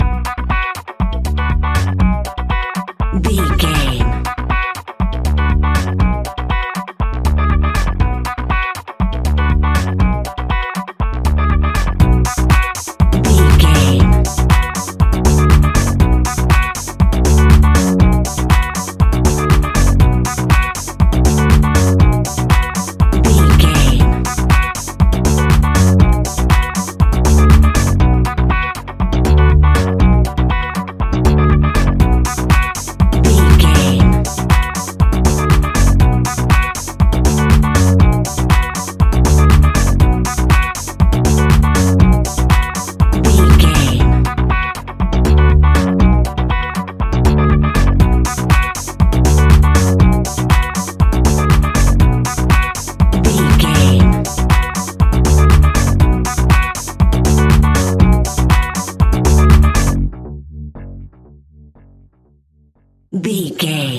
Aeolian/Minor
groovy
futuristic
uplifting
drums
electric guitar
bass guitar
funky house
electro funk
energetic
upbeat
synth leads
synth bass